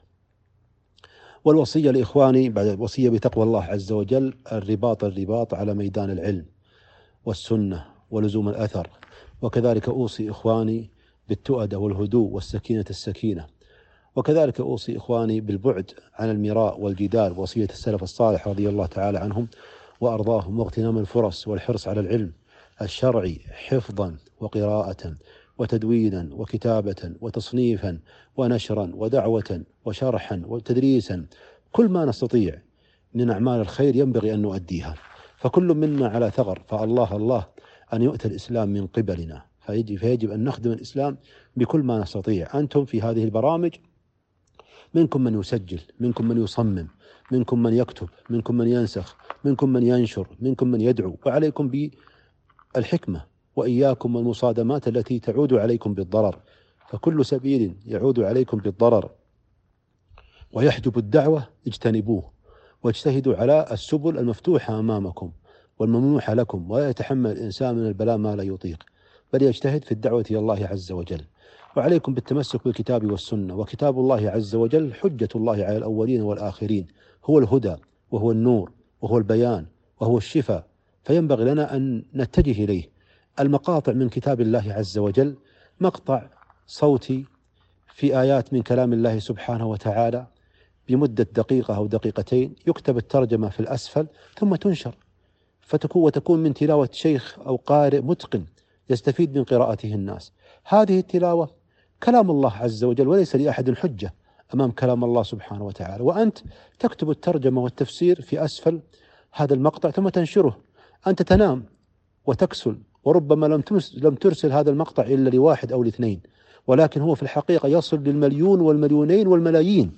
وصية هاتفية علمية لبعض طلاب العلم